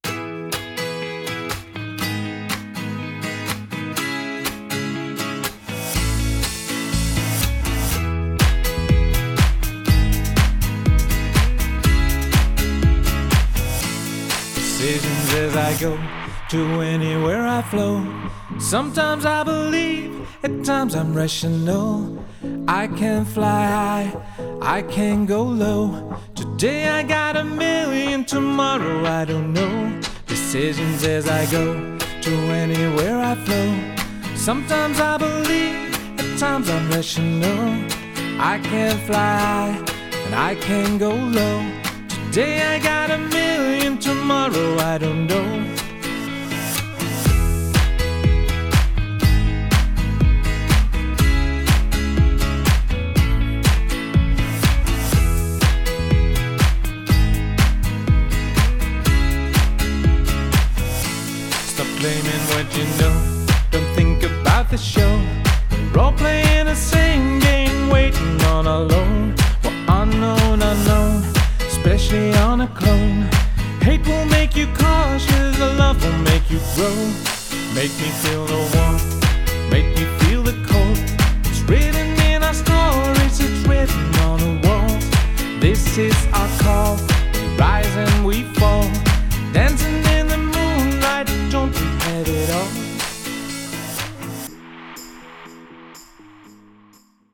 Kortom: live muziek op een professionele manier gebracht.